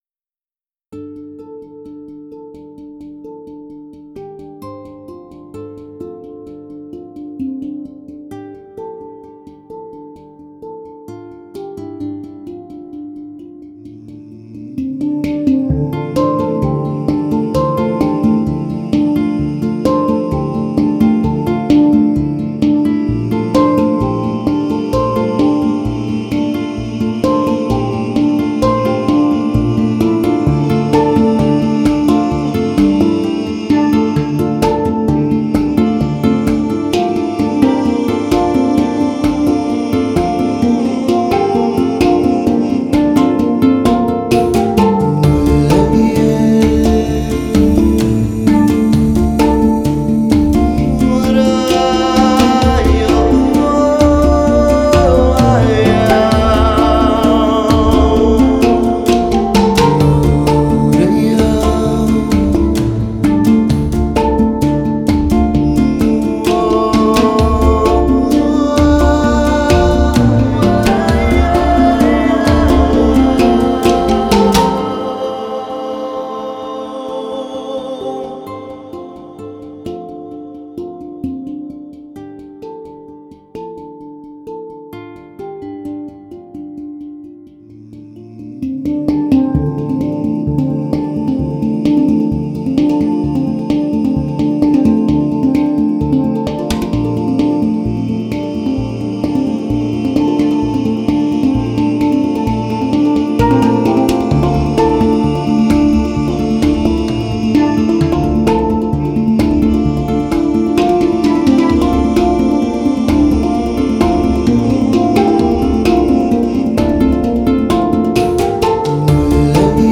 手碟 空灵 »